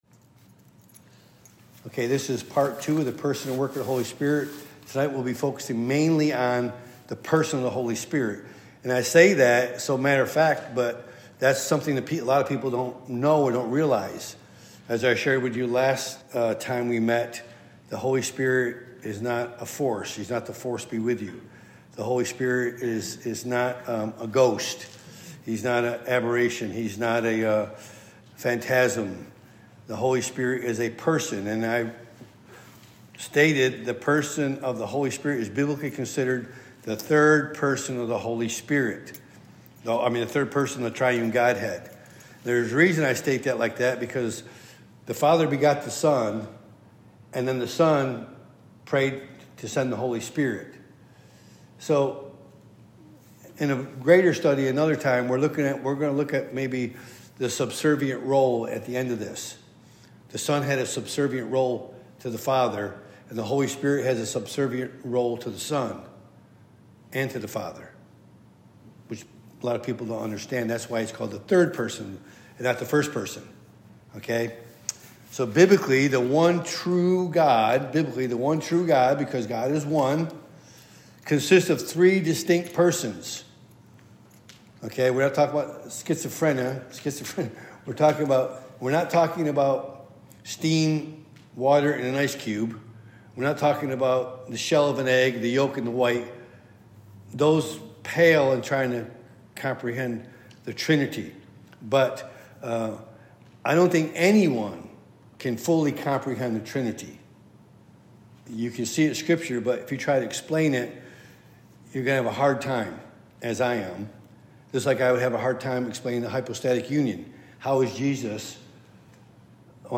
Topic: Wednesday Pastoral Bible Study